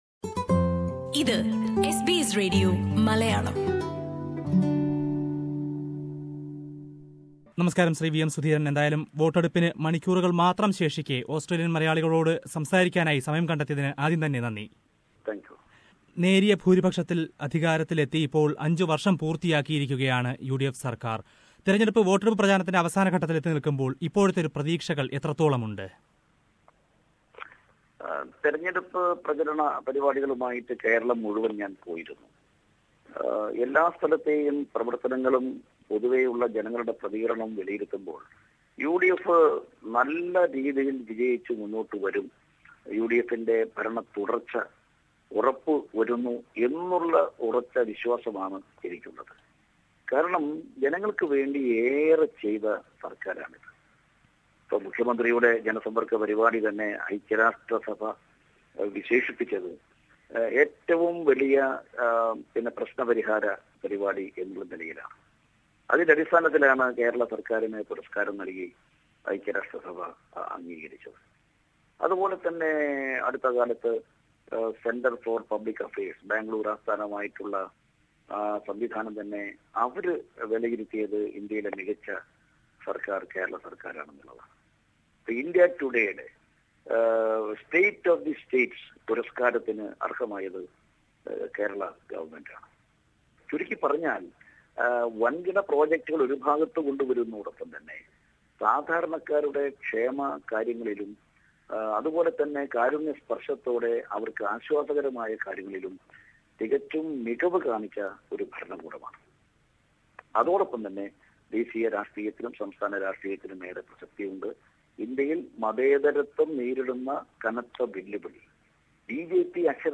കെ പി സി സി സംസ്ഥാന അധ്യക്ഷൻ വി എം സുധീരനുമായാണ് വോട്ടെടുപ്പിന് തൊട്ടുമുന്പ് എസ് ബി എസ് മലയാളം സംസാരിച്ചത്. യു ഡി എഫ് വീണ്ടും അധികാരത്തിലെത്തുകയാണെങ്കിൽ അഴിമതിക്കാർക്ക് ജയിൽ ഉറപ്പാക്കാൻ പുതിയ സംവിധാനം കൊണ്ടുവരുമെന്ന് അദ്ദേഹം പറഞ്ഞു. കേരളത്തിൽ ബി ജെ പി നടത്തുന്നത് വെറും ആഡംബര പ്രകടനം മാത്രമാണെന്നും, ബി ജെ പി അക്കൌണ്ട് തുറക്കില്ലെന്നും സുധീരൻ അഭിപ്രായപ്പെട്ടു. അഭിമുഖം കേൾക്കാം, മുകളിലെ പ്ലേയറിൽ നിന്ന്...